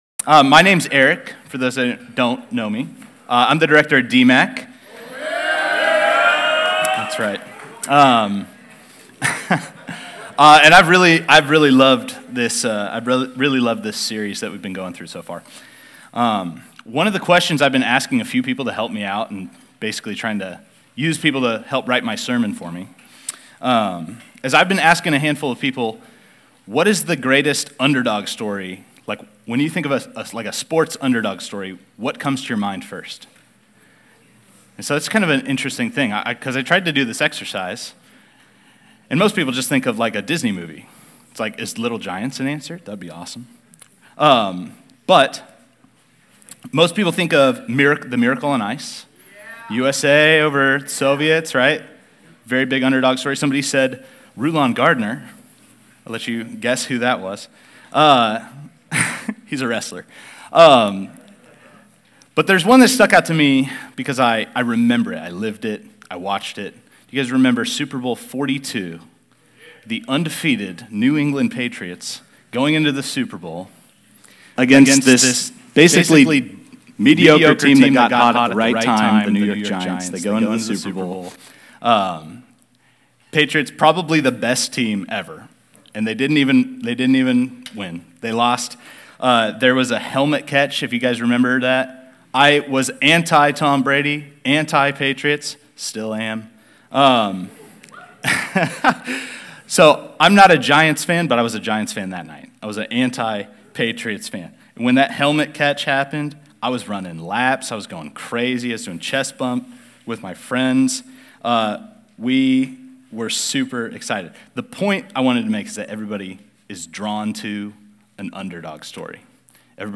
David | Fall Conference 2024 | Shadows of the Son - Campus Fellowship